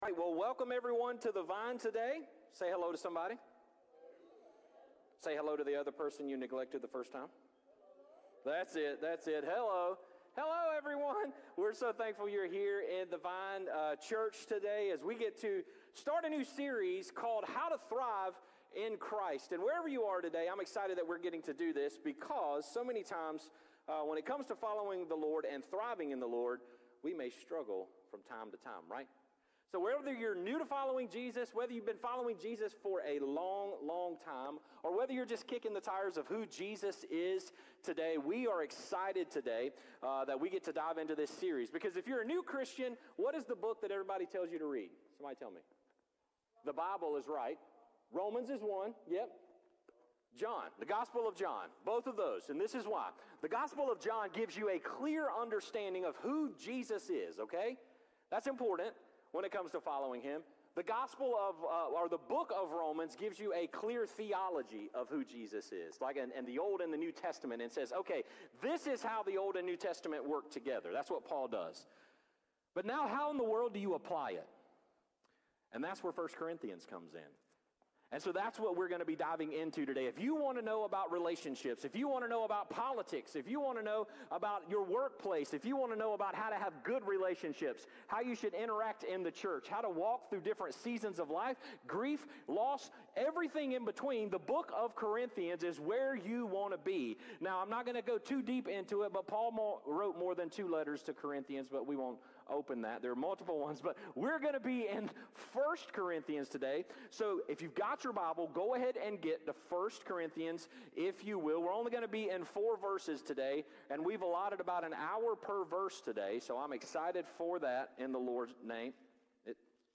Sermons | The Vine Church